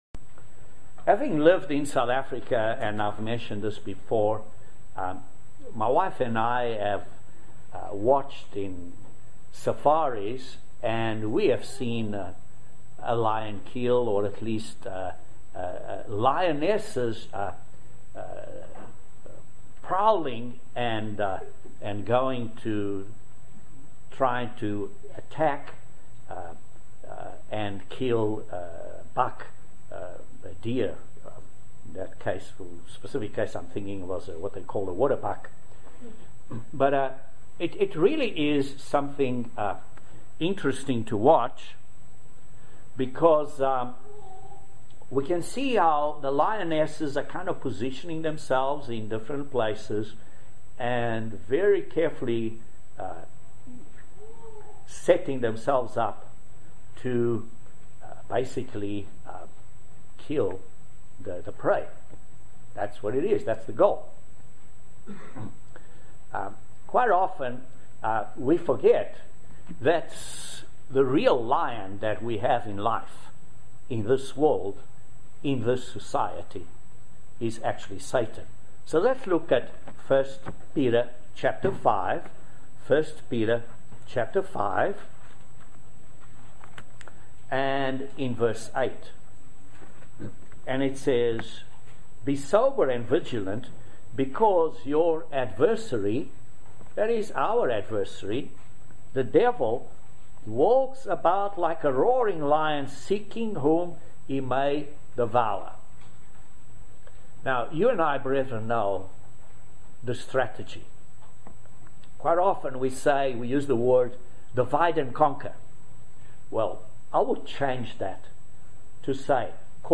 Please join us for this eyeopening video sermon on the subject of Satan and some of his tricks and deceptions. In these times we are living in, we need to be aware of our adversary , and all his methods of division, untruths, and hatred of God and God's people.